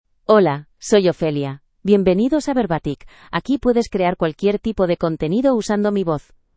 FemaleSpanish (Spain)
Ophelia is a female AI voice for Spanish (Spain).
Voice sample
Listen to Ophelia's female Spanish voice.
Ophelia delivers clear pronunciation with authentic Spain Spanish intonation, making your content sound professionally produced.